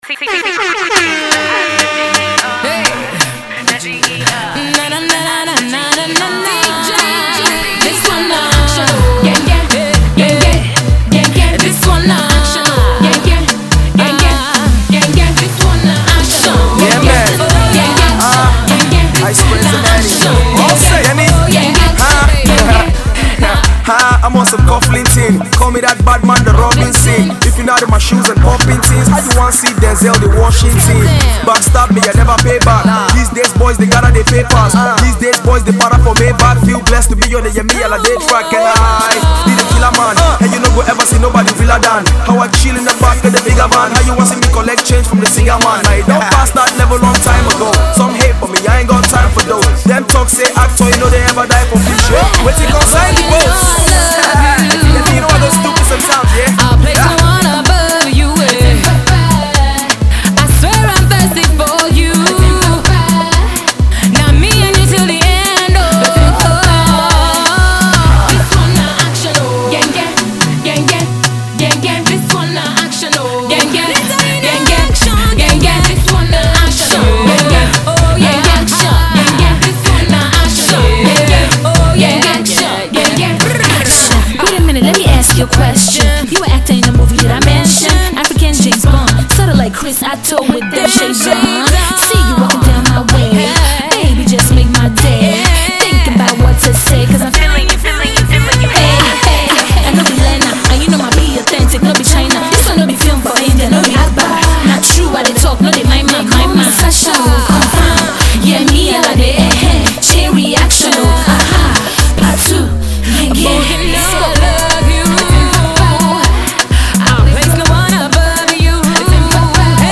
Afro Pop star